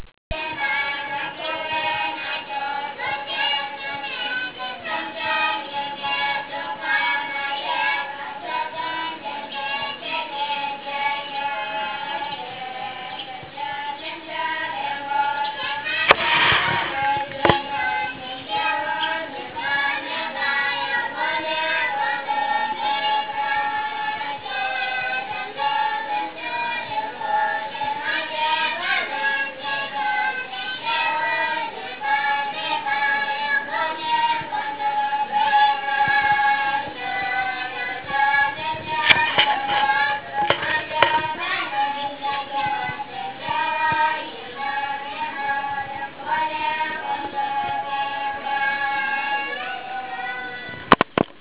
il monastero di Jarkhot
per sentire la litania dei piccoli monaci
monaci.wav